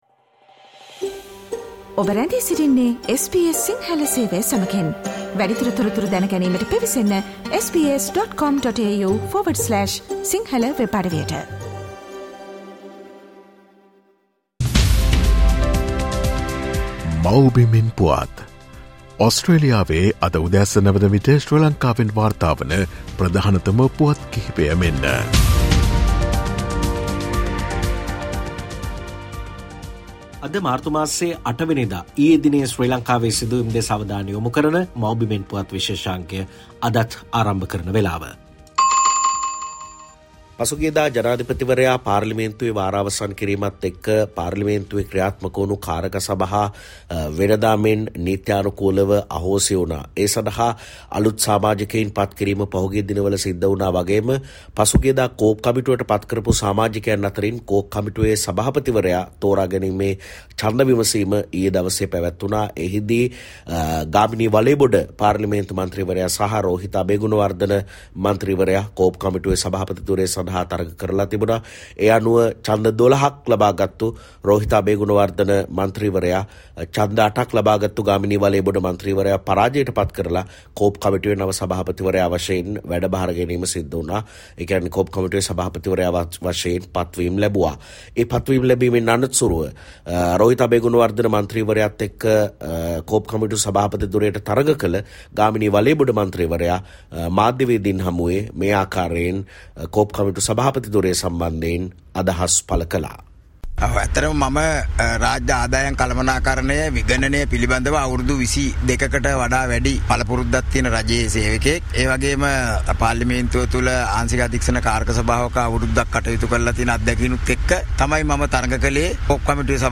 SBS Sinhala featuring the latest news reported from Sri Lanka - Mawbimen Puwath